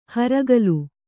♪ haragalu